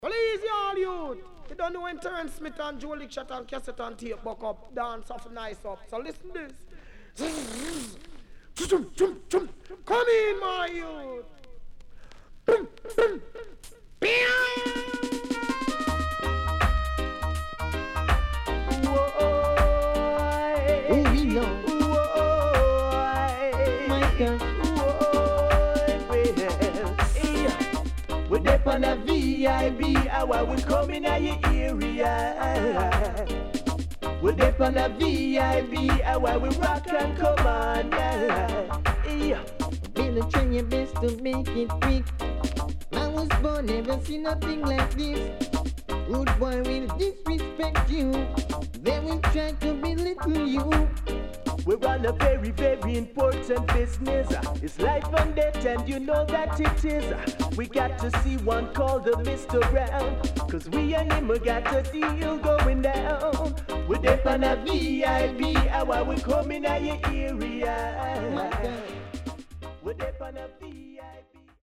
CONDITION SIDE A:VG(OK)〜VG+
Foundation & Dancehall
SIDE A:所々チリノイズがあり、少しプチノイズ入ります。